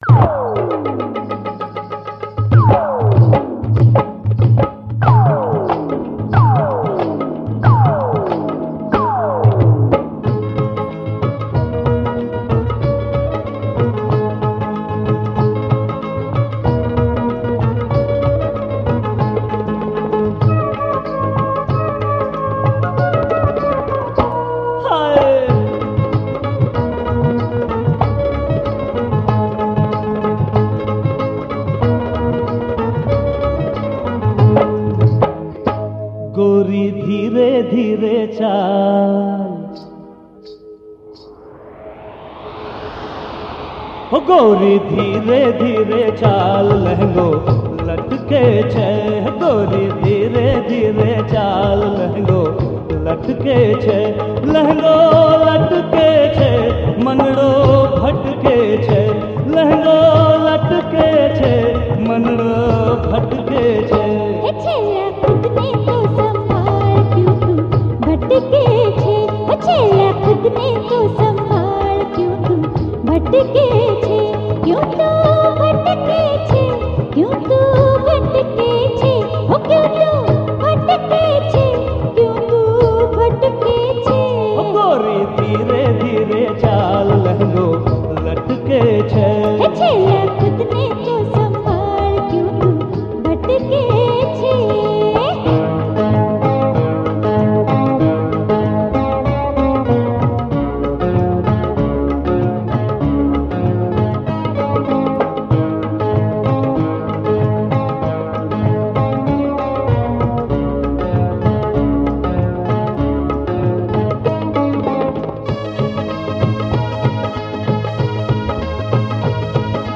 Rajasthani Folk Songs